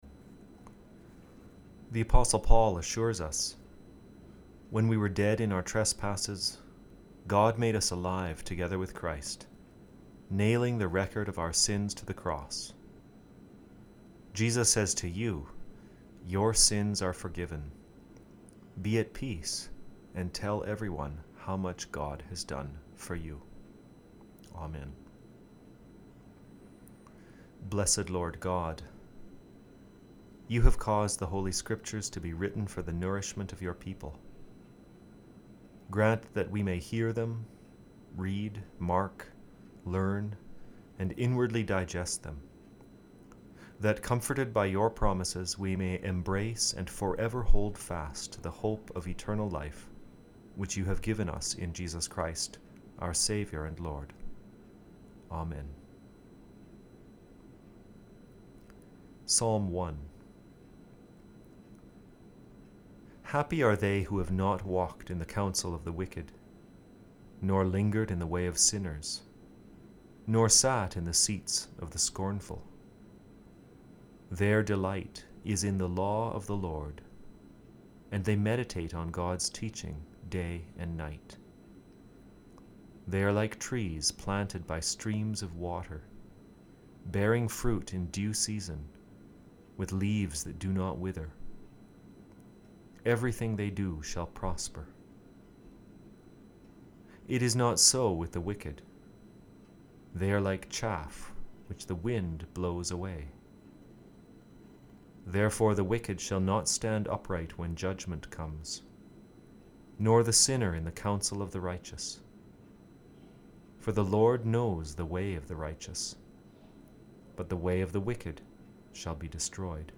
20 minutes of guided prayer and scripture reflection